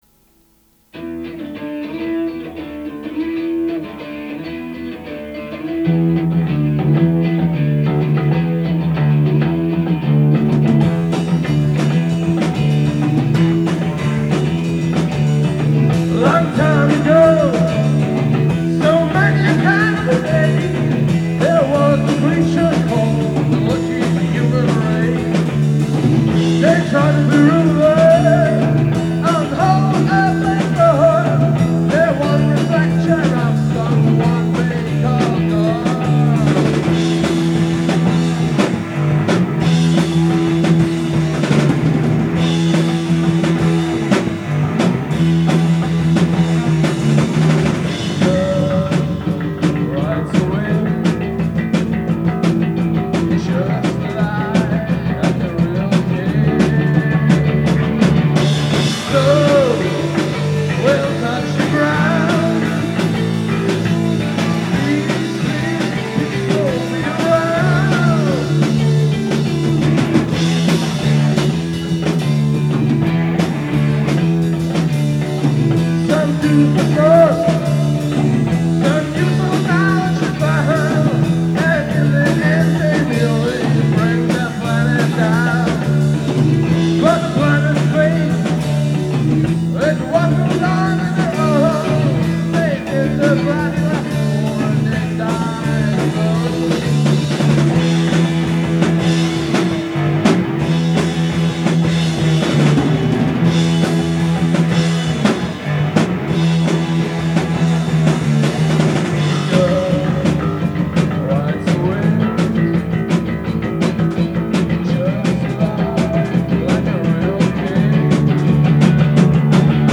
Im Proberaum - Hallergasse - aufgenommen.
Hat einen 60´s touch.